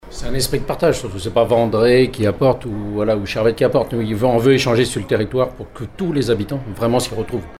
Un sentiment partagé par le maire de Vandré Pascal Tardy :
Les élus se sont exprimés hier soir à l’occasion des vœux de la CdC Aunis Sud qui se sont tenus symboliquement à La Devise, devant 200 invités et un parterre d’élus locaux.